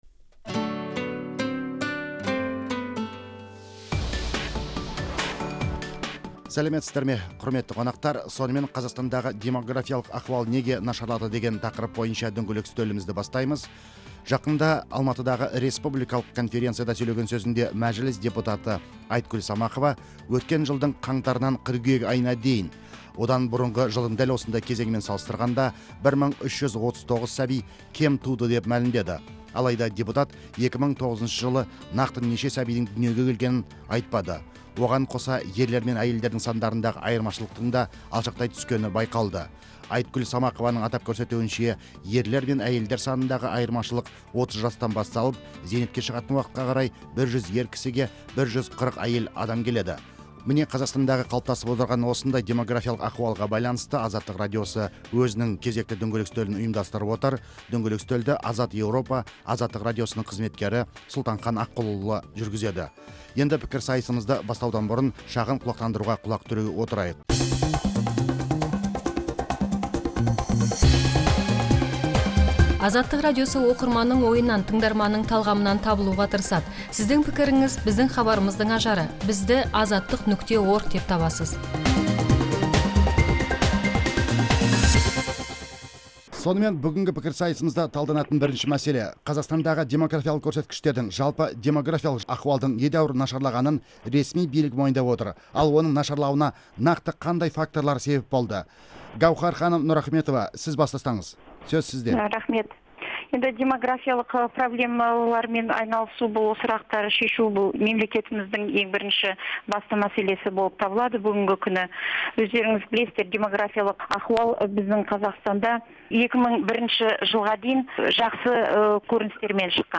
Қазақстан:демографиялық ахуал жайында дөңгелек үстел сұхбаты